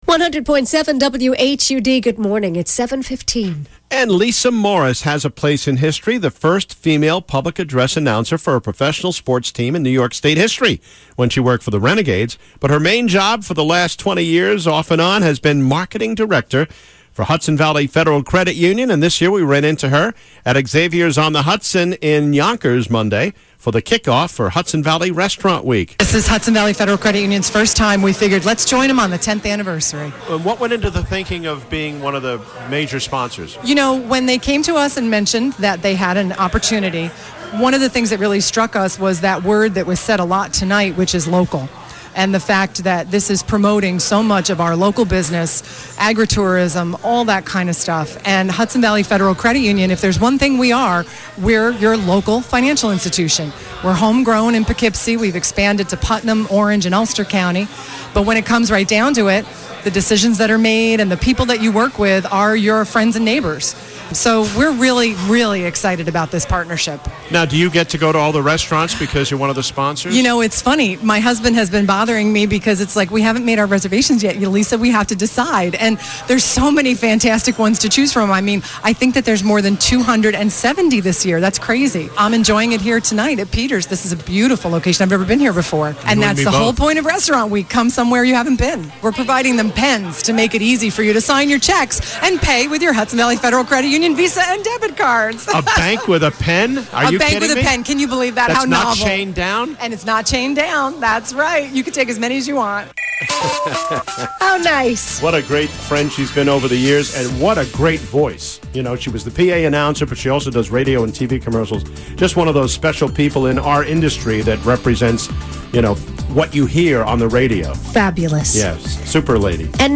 Hudson Valley Restaurant Week interview